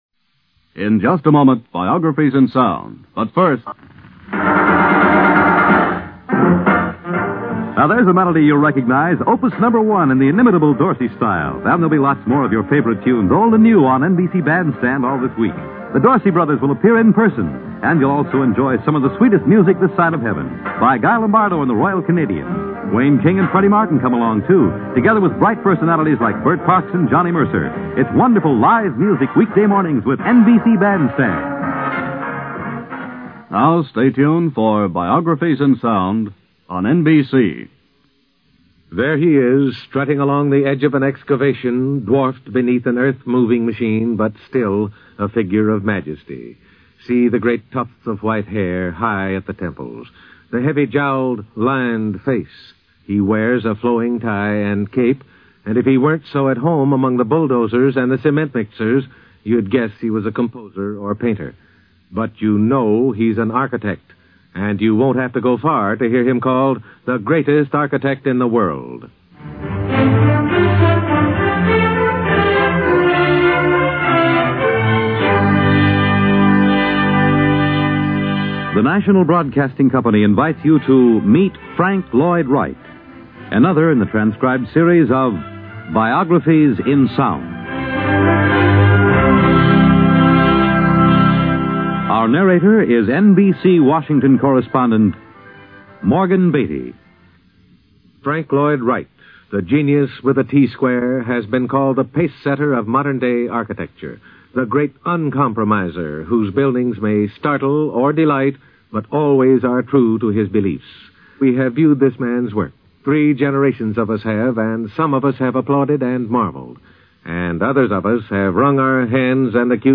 Featuring Morgan Beatty narrator